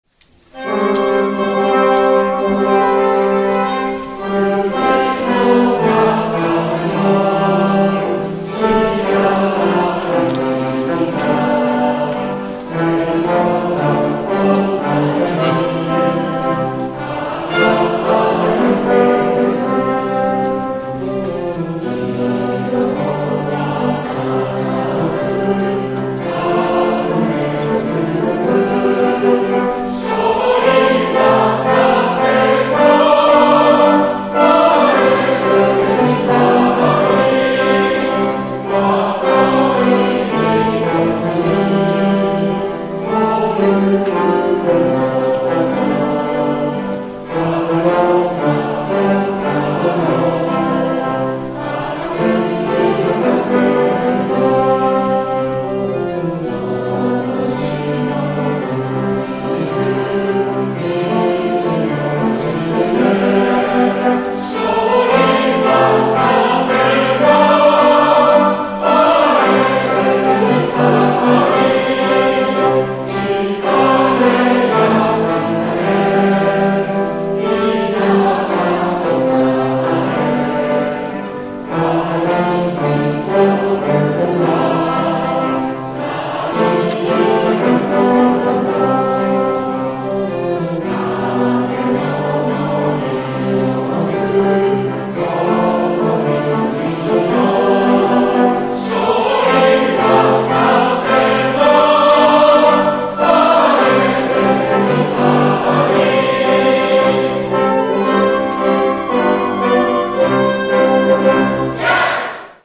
応援歌